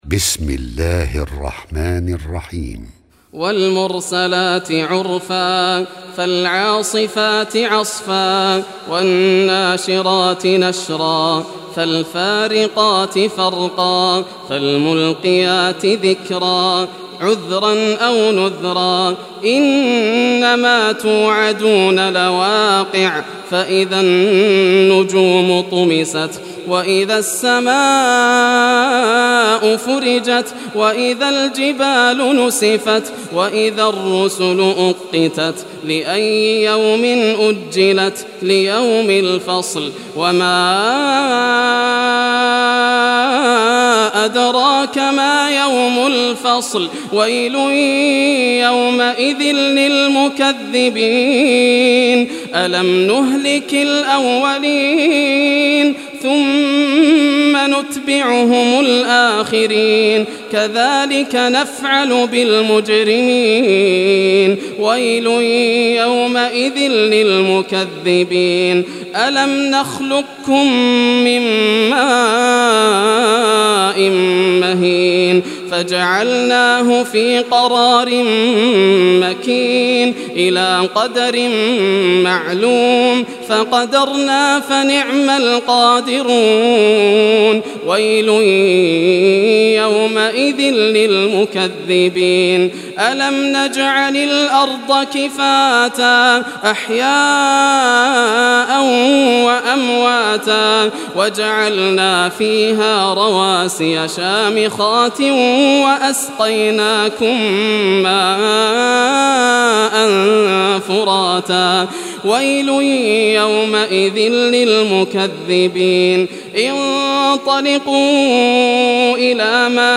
Surah Al-Mursalat Recitation by Yasser al Dosari
Surah Al-Mursalat, listen or play online mp3 tilawat / recitation in Arabic in the beautiful voice of Sheikh Yasser al Dosari.